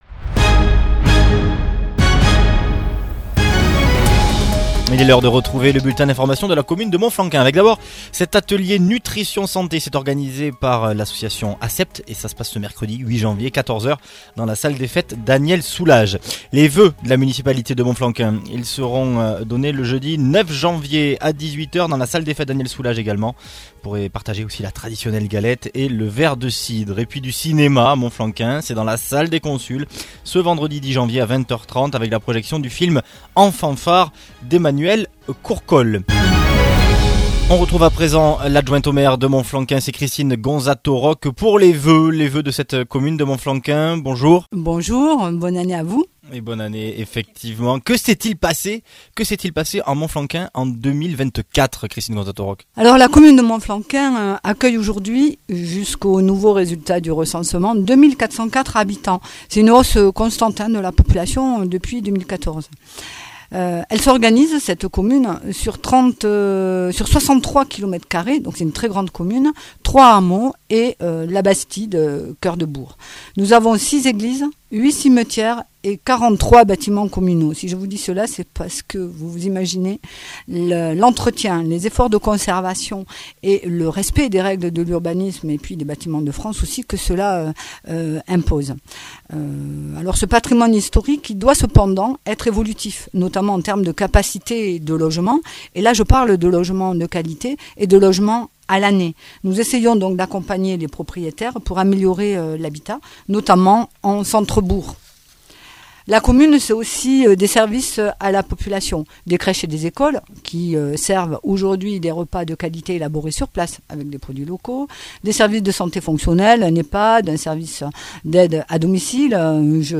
VOS ÉLU(E)S  à la RADIO